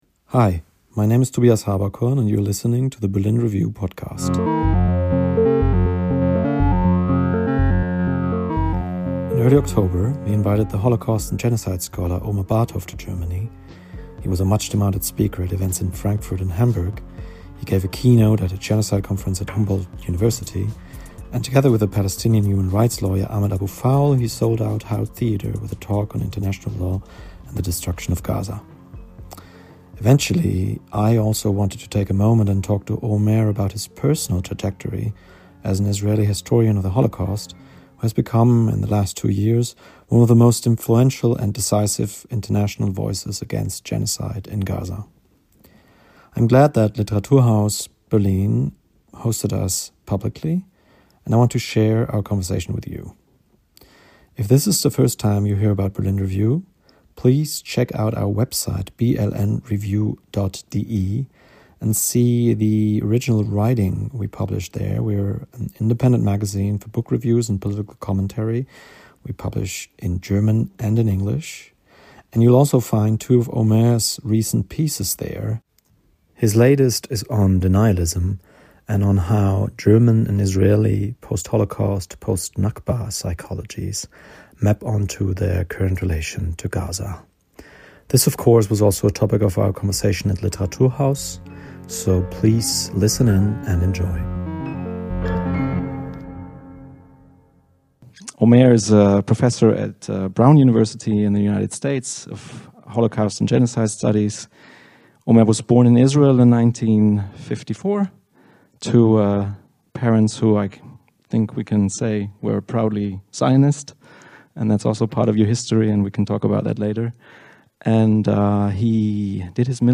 live from Li-Be Literaturhaus Berlin, recorded on Oct 12, 2025.